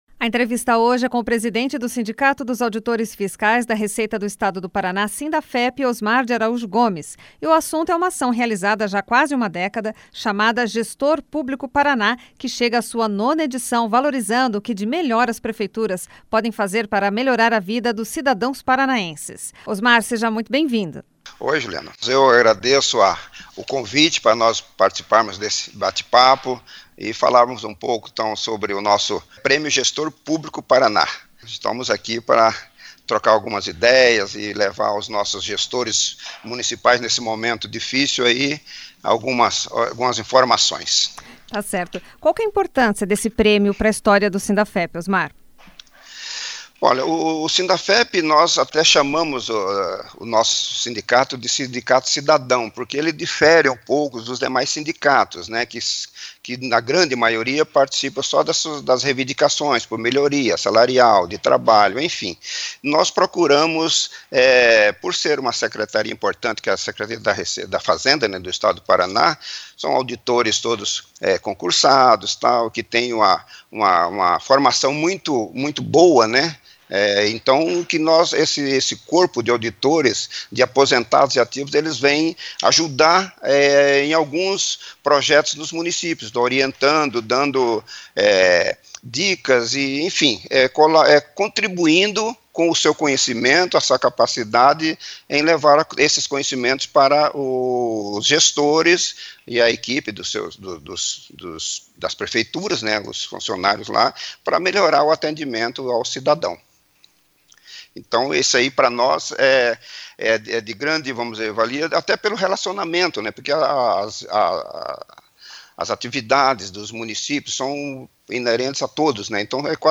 ENTREVISTA: Em busca dos prefeitos com ideias transformadoras